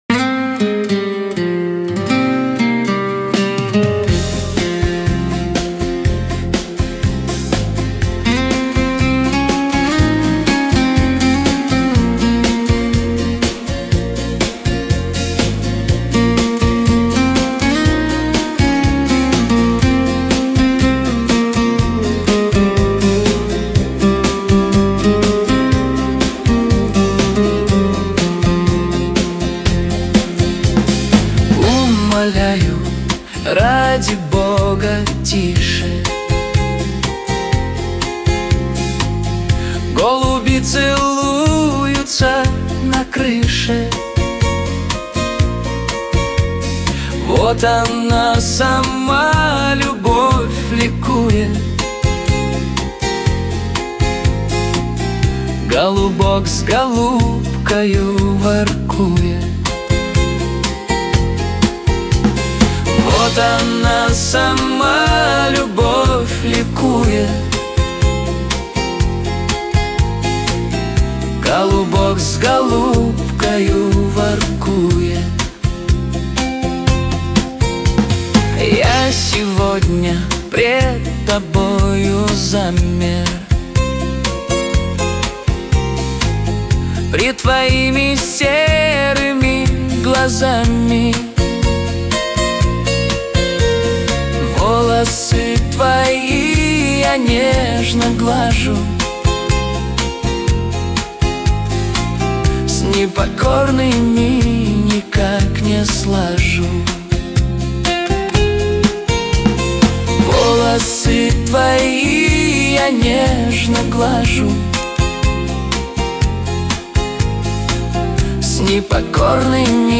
13 декабрь 2025 Русская AI музыка 74 прослушиваний